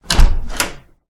dooropen3.ogg